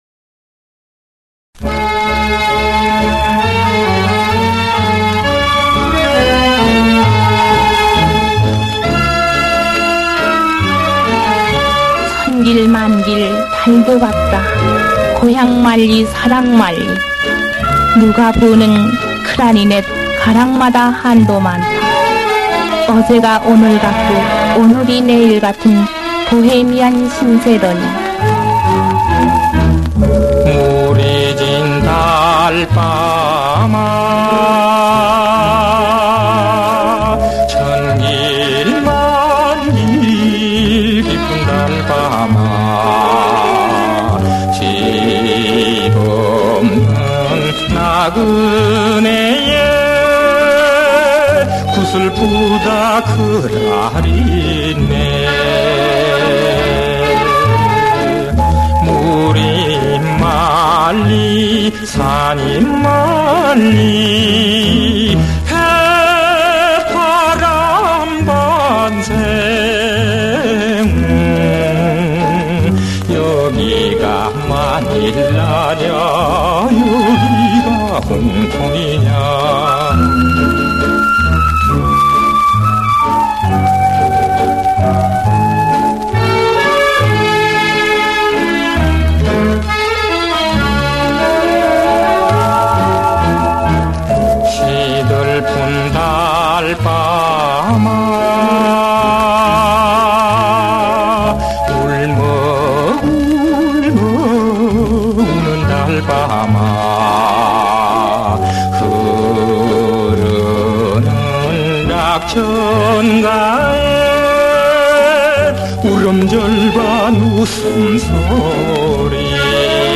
"대사 생략"